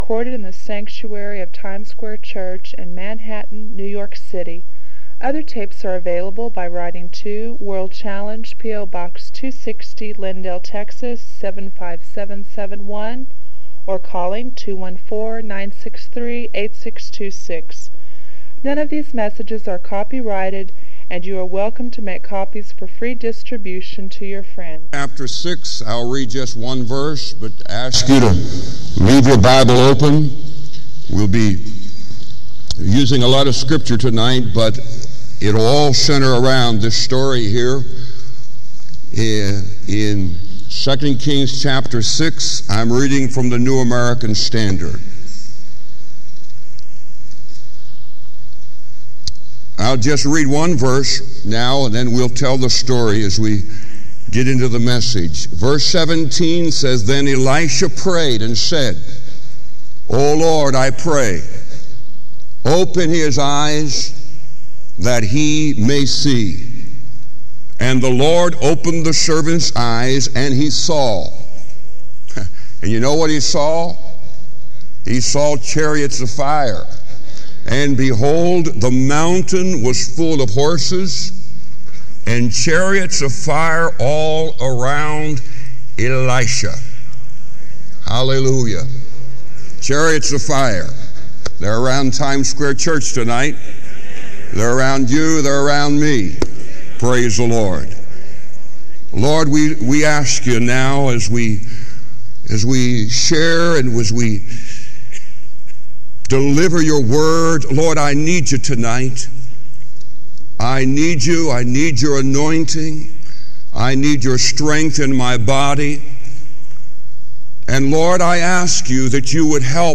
This sermon offers hope and practical insight for those facing spiritual battles today.